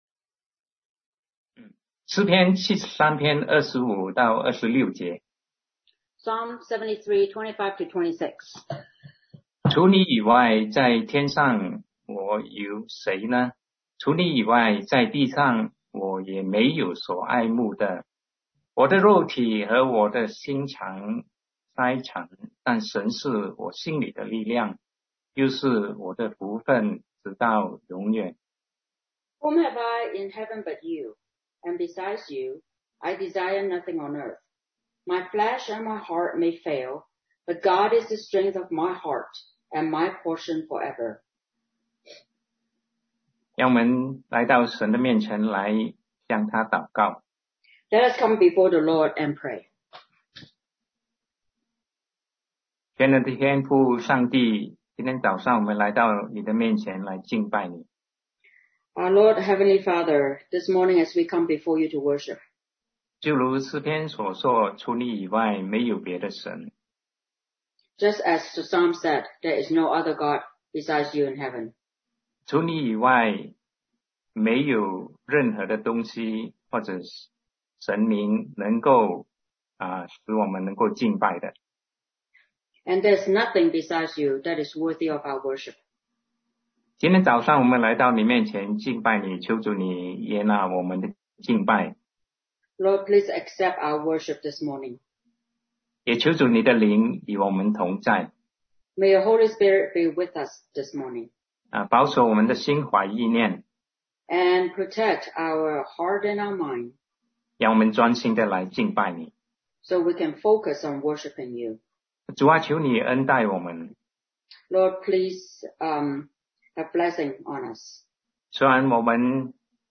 Sermon 2020-07-12 Elihu’s Points of View – God is Superb and Great